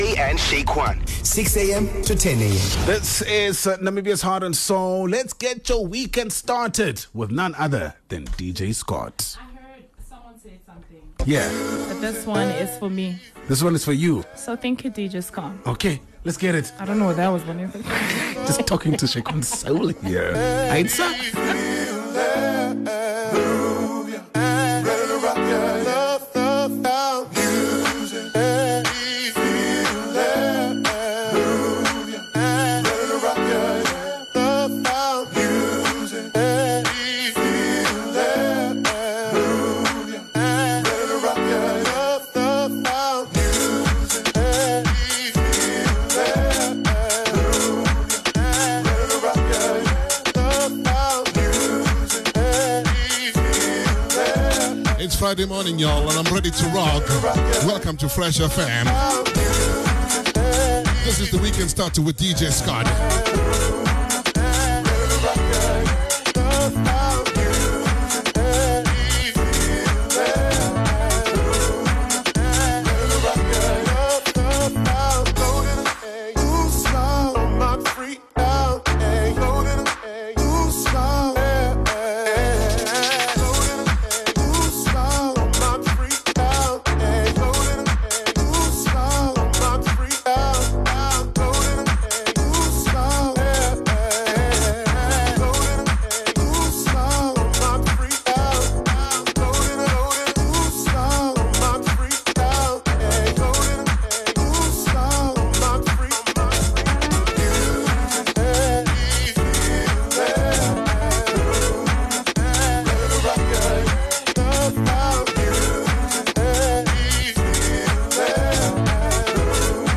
features good old funk!